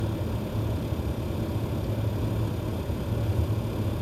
Echter, de pomp maakt een zacht ratelend geluidje.
Audio opname van het ratelgeluidje, met Galaxy S10 opgenomen vlak naast de pomp:
Geruis op de achtergrond is van de casefans en ja, die heb ik allemaal al een keer gestopt om uit te sluiten dat het geluid daar vandaan komt.
ratel.mp3